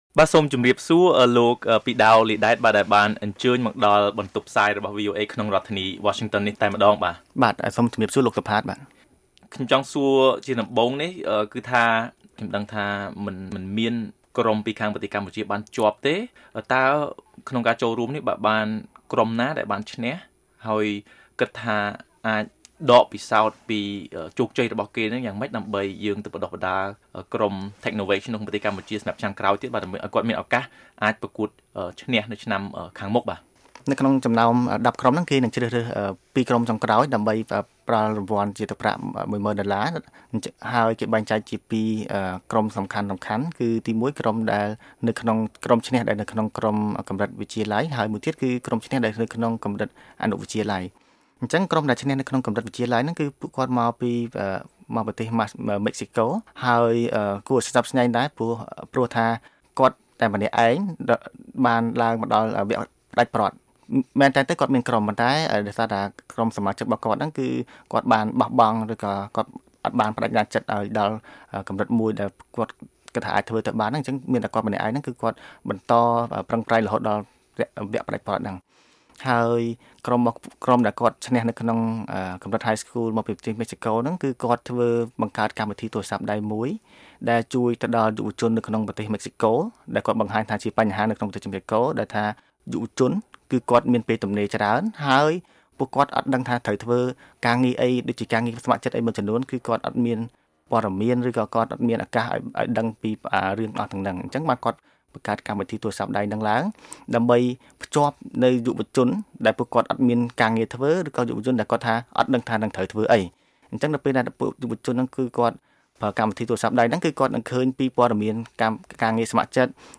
បទសម្ភាសន៍ VOA៖ អ្នកបណ្តុះបណ្តាលថា យុវតីខ្មែរមានសមត្ថភាពខ្ពស់ក្លាយជាសហគ្រិនខាងបច្ចេកវិទ្យា